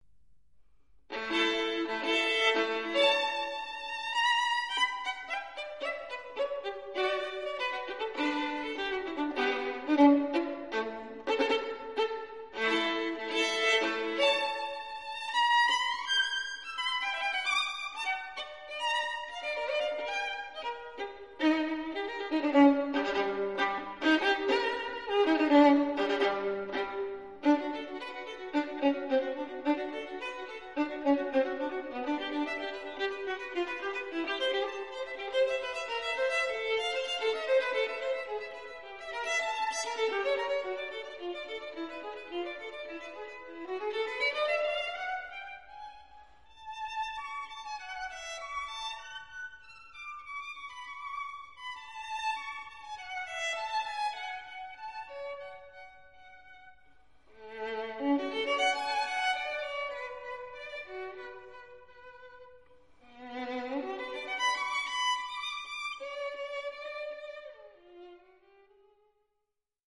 Sonata for Violin Solo in D major Op. 115